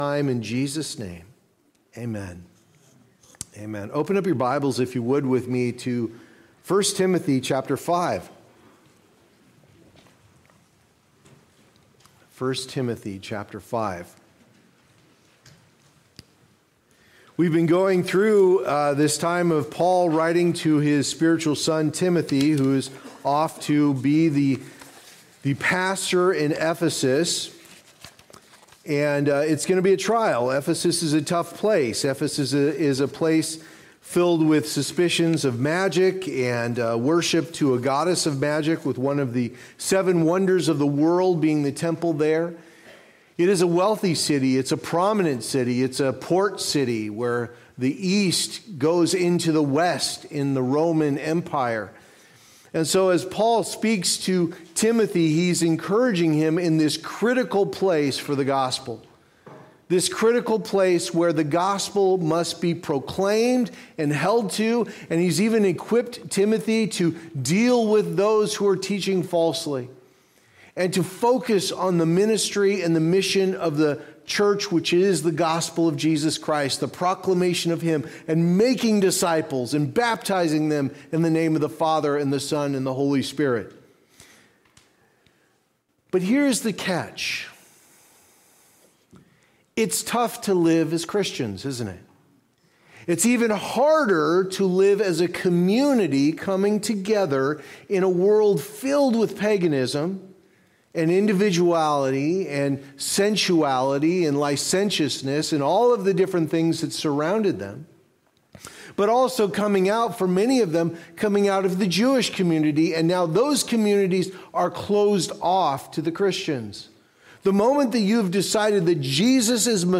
Guard the Gospel & Shepherd the Flock Passage: I Timothy 5:1-16 Services: Sunday Morning Service Download Files Notes Previous Next